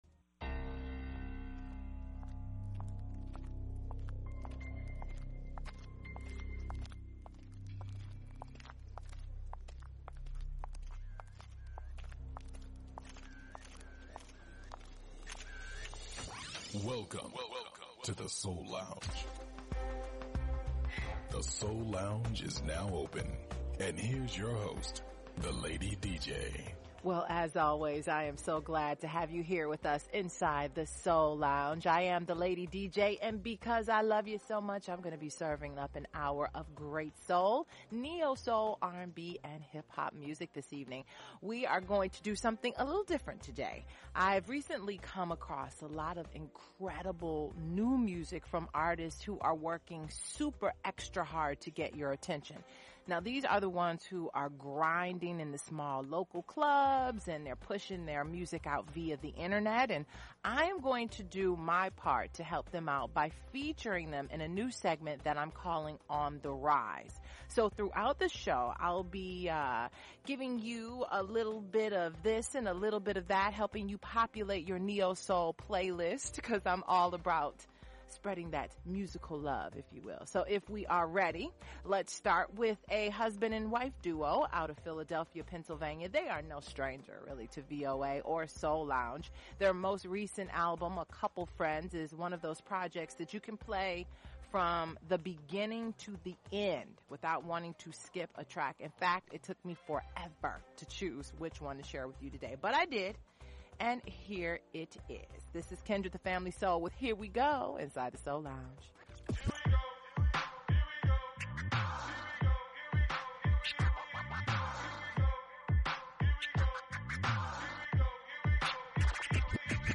Neo-Soul
conscious Hip-Hop
Classic Soul